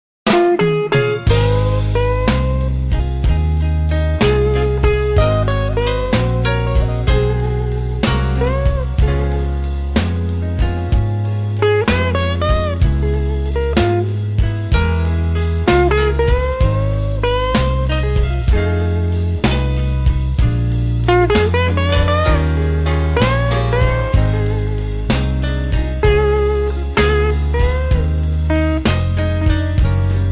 イントロソロのみを再生